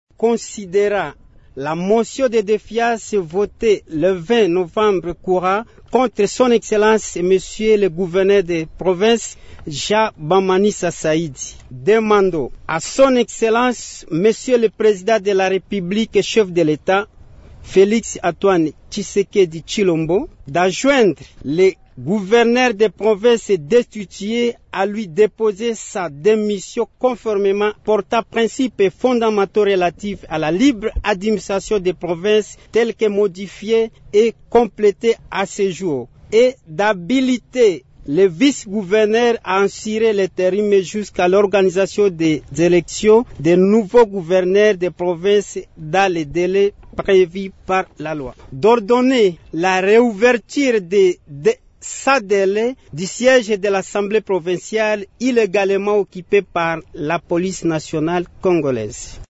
Ils l’ont dit vendredi 29 novembre au cours d’un point de presse tenu à Bunia.
Vous pouvez écouter le rapporteur adjoint du bureau de l’assemblée provinciale, Christian Endite Sukari dans cet extrait :